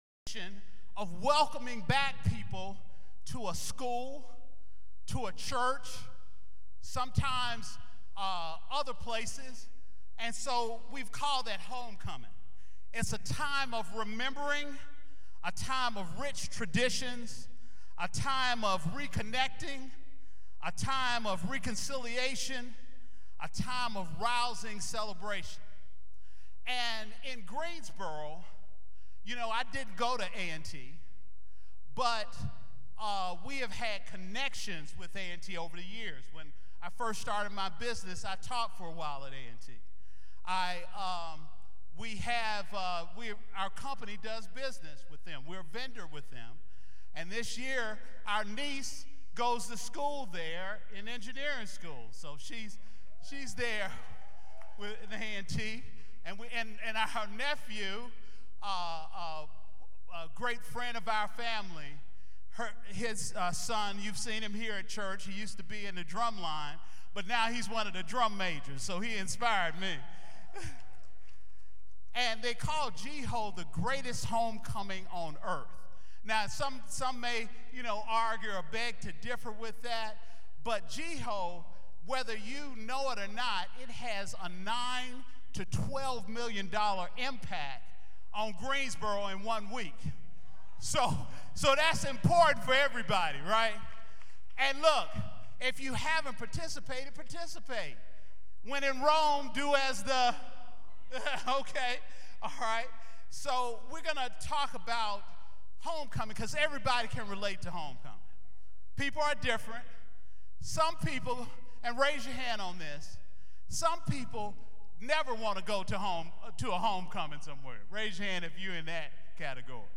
Homecoming Service